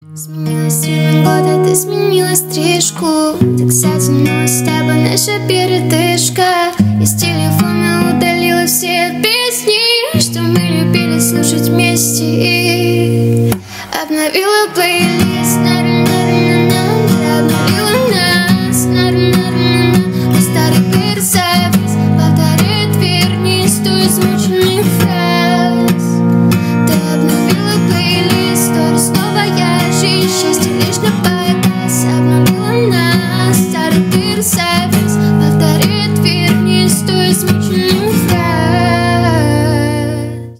бесплатный рингтон в виде самого яркого фрагмента из песни
Поп Музыка
грустные # кавер